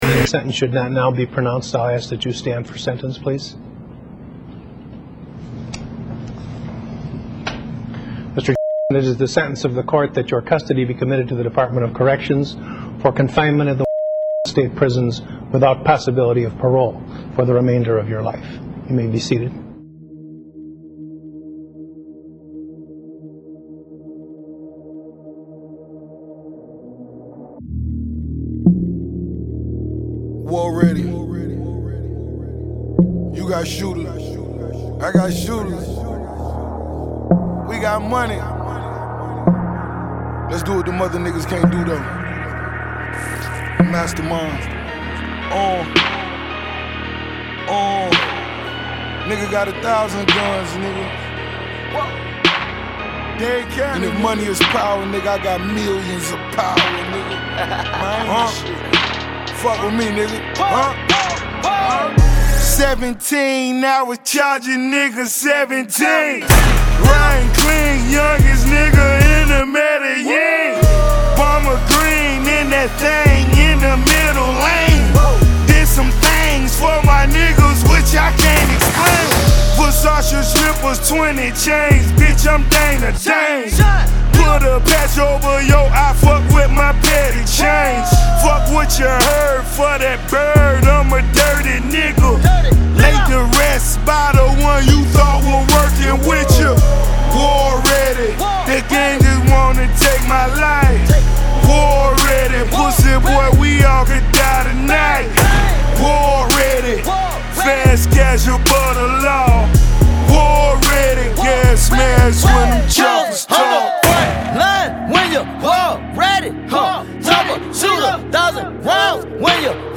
in the sinister “trap” vein